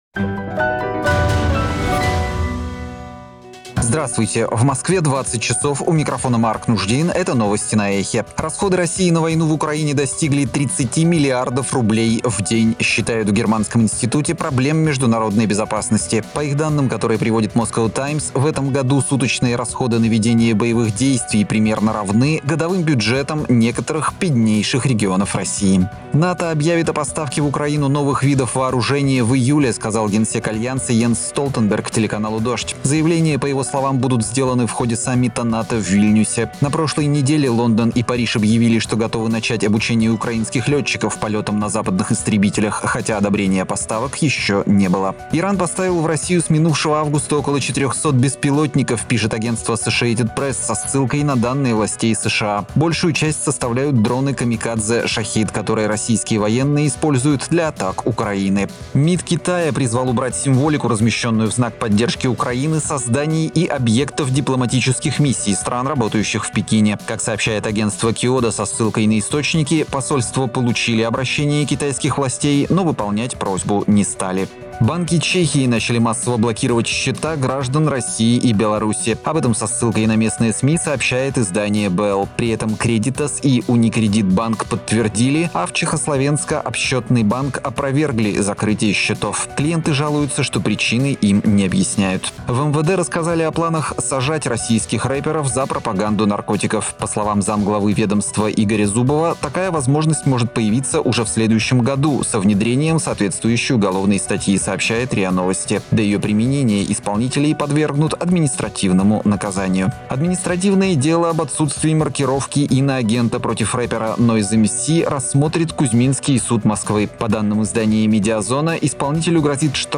Слушайте свежий выпуск новостей «Эха»…